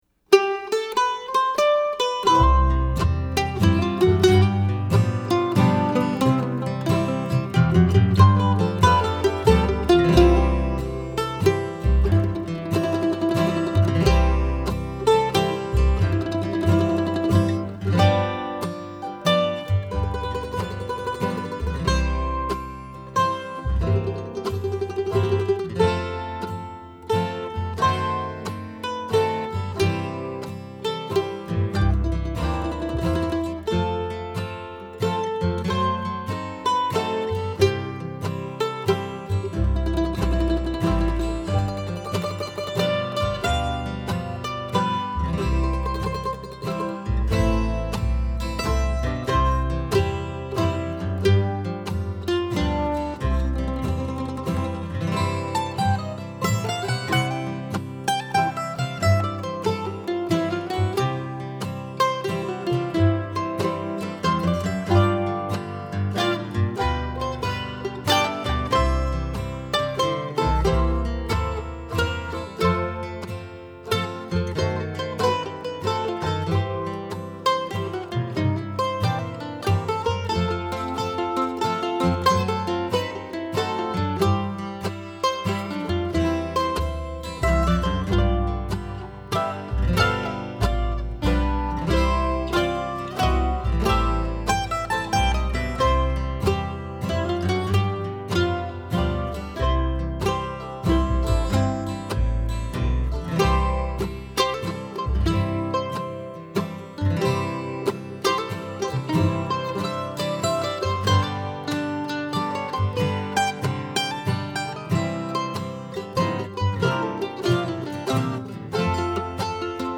DIGITAL SHEET MUSIC - MANDOLIN SOLO
• Christmas, Bluegrass,Mandolin Solo